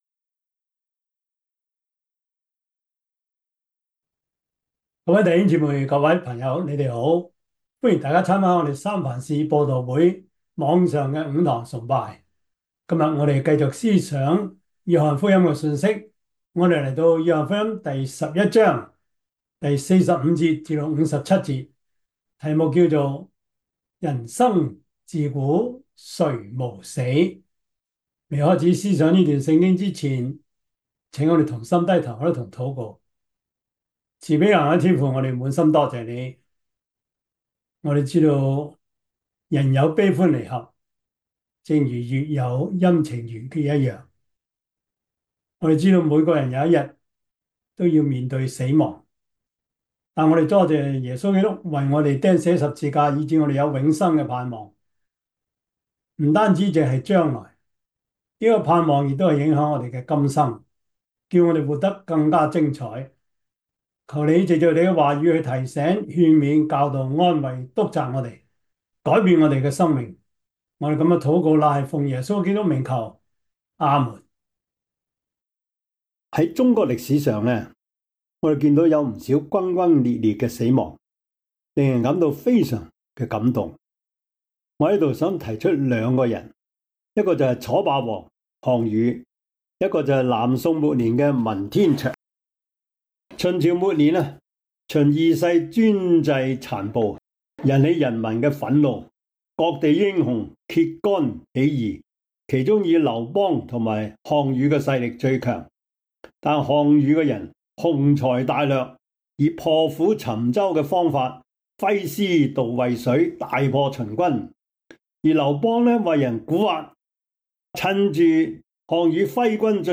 Service Type: 主日崇拜
Topics: 主日證道 « 愛是凡事盼望 認識精神病及情緒病 – 01 »